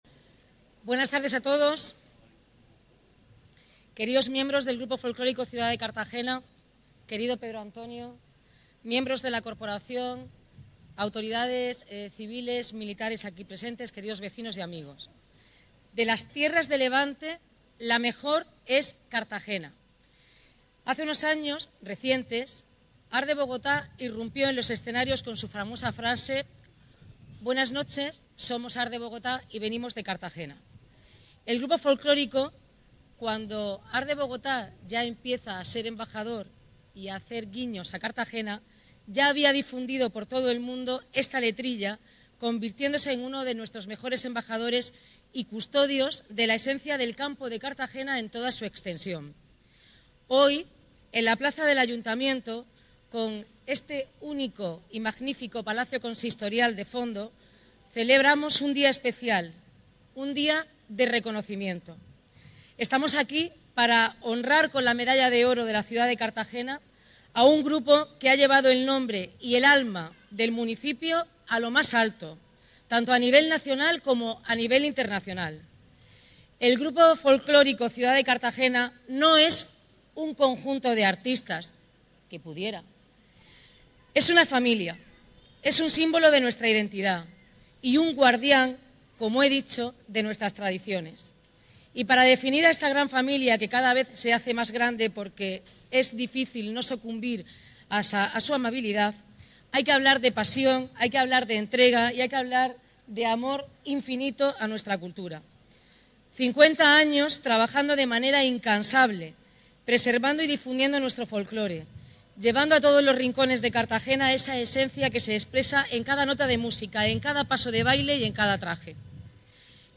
Enlace a Declaraciones de la alcaldesa Noelia Arroyo
La Medalla de Oro de Cartagena, una de las más altas distinciones que otorga la ciudad, ya luce en forma de corbatín en el estandarte del Grupo Folclórico Ciudad de Cartagena de La Palma, tras el multitudinario acto de entrega celebrado este sábado en la plaza del Ayuntamiento a las puertas del Palacio Consistorial.